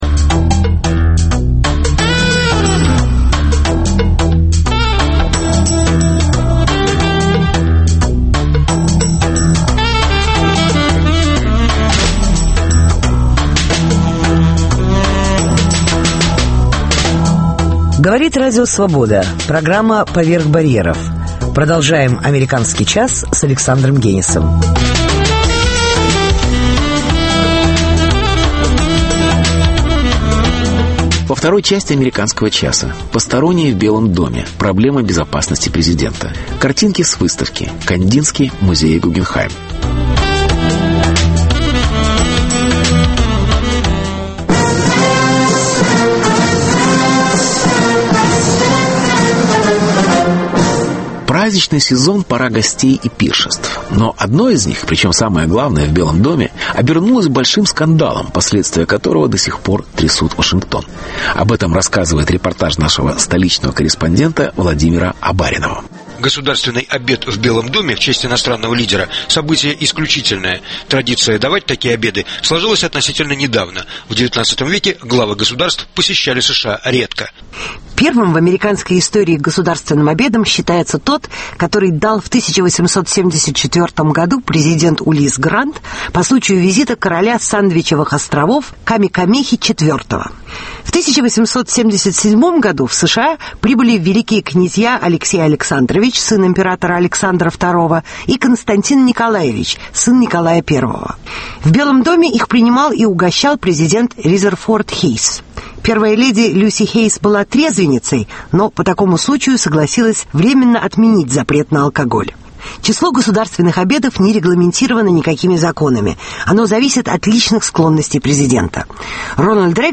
Репортаж. Посторонние в Белом Доме.